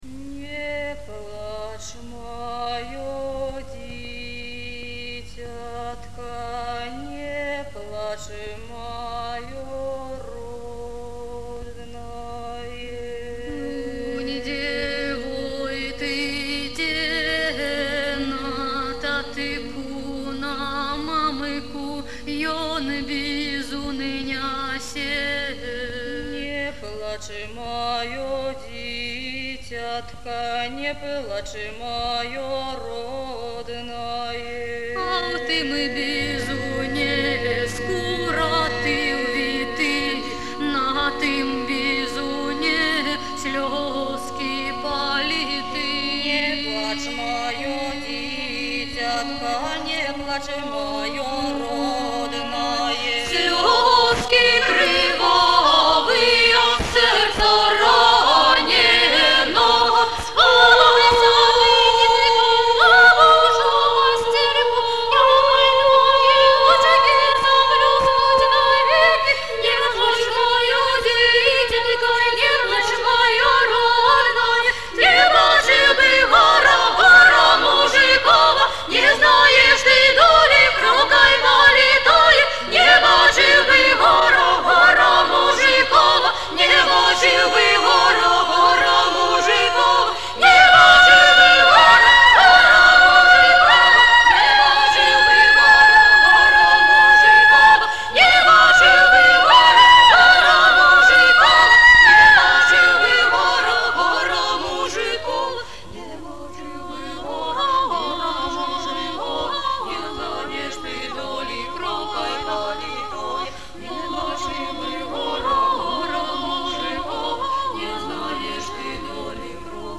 "Каралёначкі" сьпяваюць калыханку. Архіўны запіс.